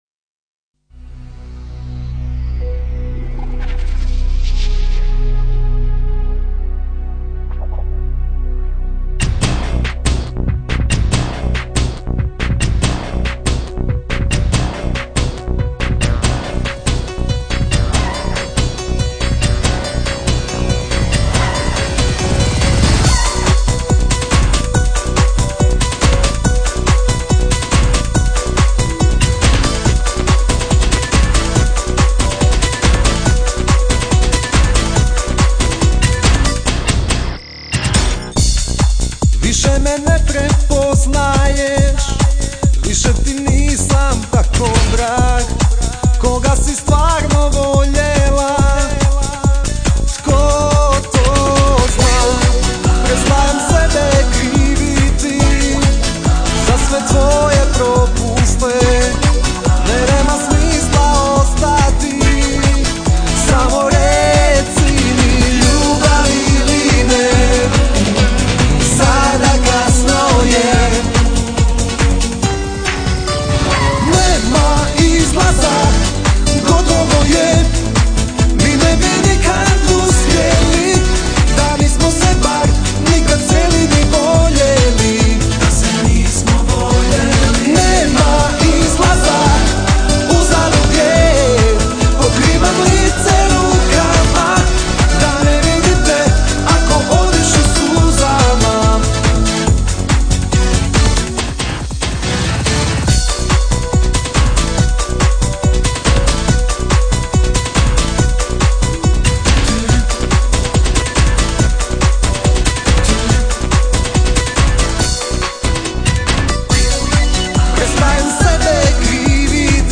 lo-fi, stereo
eksperimentalni song